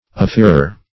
Search Result for " affeeror" : The Collaborative International Dictionary of English v.0.48: Affeerer \Af*feer"er\, Affeeror \Af*feer"or\, n. [OF. aforeur, LL. afforator.]